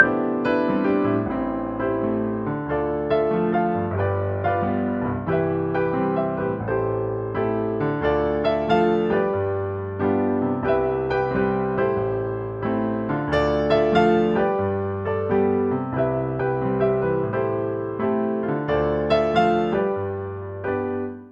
描述：山顶休息室爵士乐钢琴
Tag: 80 bpm Jazz Loops Piano Loops 3.59 MB wav Key : Unknown